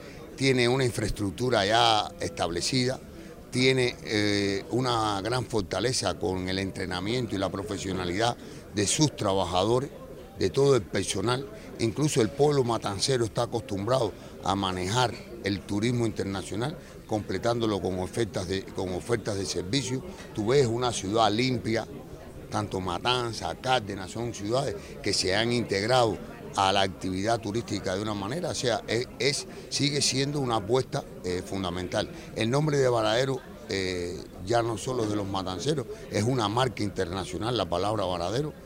Así lo reconoció el Ministro de Turismo Juan Carlos García Granda durante un intercambio con la prensa matancera en el Aeropuerto Internacional Juan Gualberto Gómez,de Varadero.